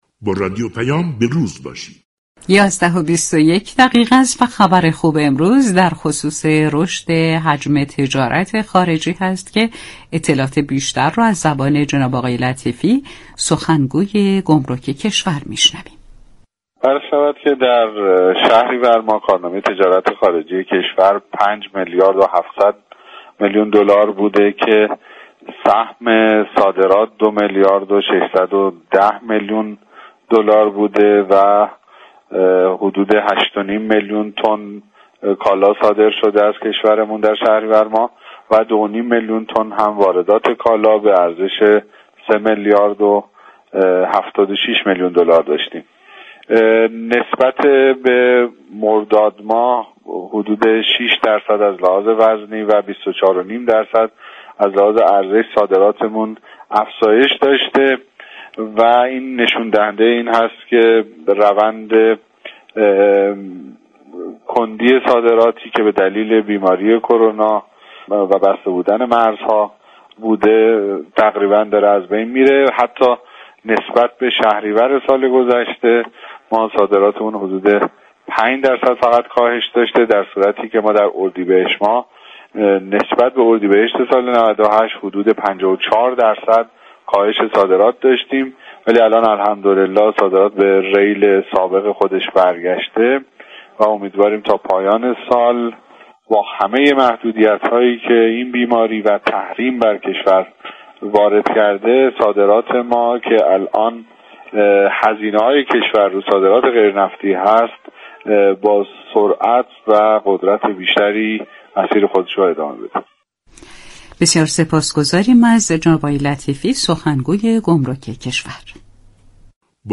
در گفتگو با رادیو پیام